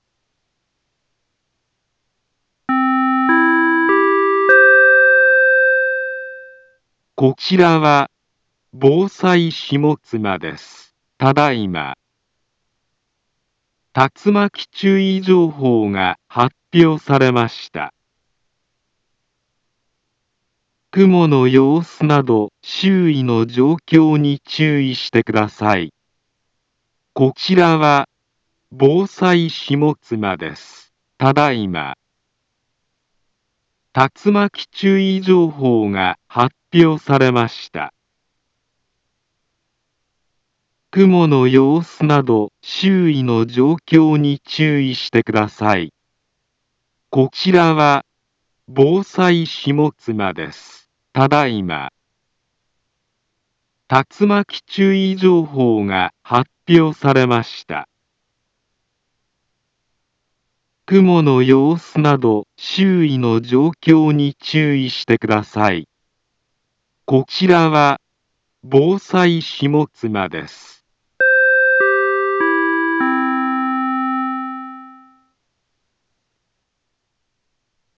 Back Home Ｊアラート情報 音声放送 再生 災害情報 カテゴリ：J-ALERT 登録日時：2024-06-28 15:04:33 インフォメーション：茨城県南部は、竜巻などの激しい突風が発生しやすい気象状況になっています。